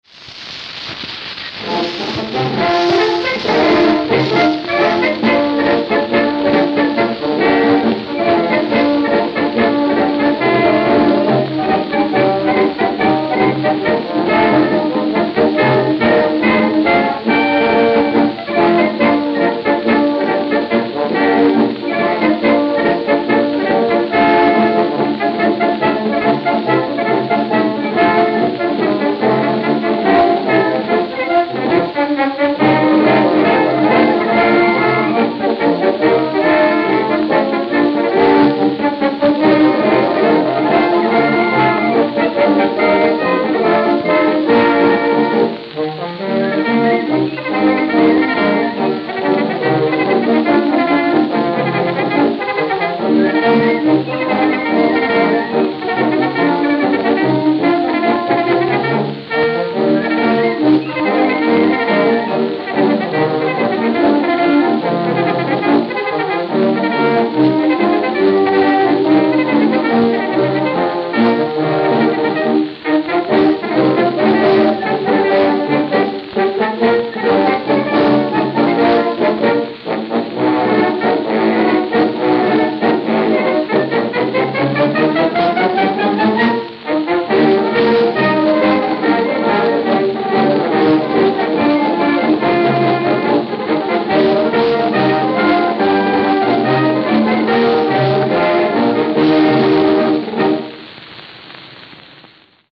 Band